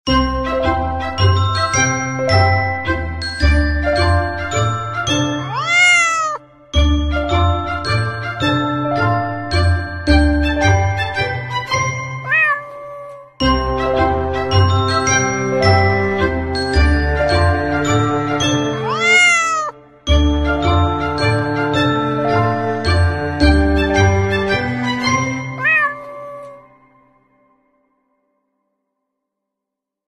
Lectric hawk parrot toy with sound effects free download
Lectric hawk parrot toy with sound and light electric hang wire flying hawk children simulate parrot toy bird